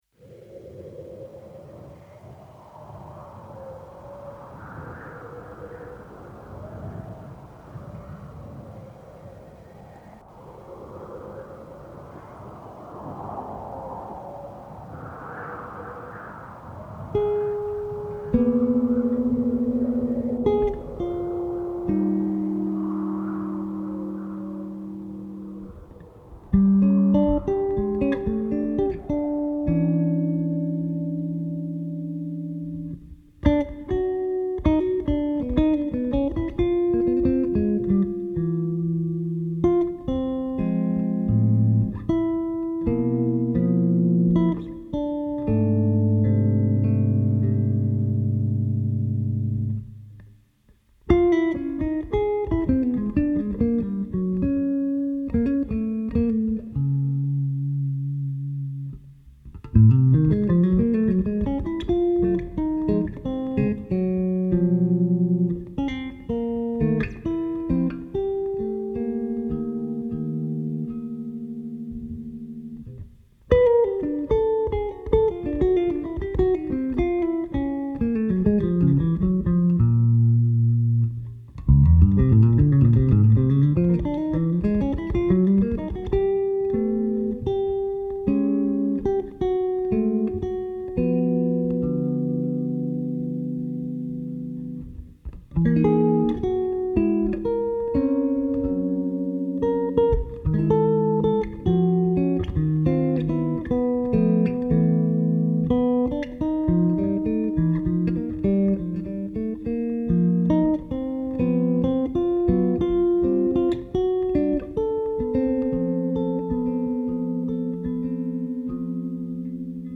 Drums & Percussion
Vocals, Waterphone
Piano & Keyboards
Electric Bass